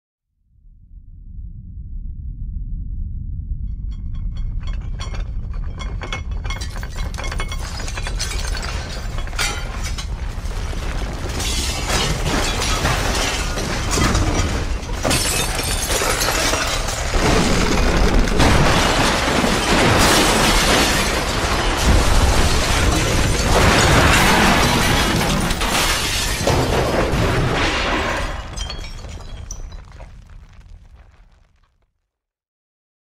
دانلود آهنگ زمین لرزه 2 از افکت صوتی طبیعت و محیط
دانلود صدای زمین لرزه 2 از ساعد نیوز با لینک مستقیم و کیفیت بالا
جلوه های صوتی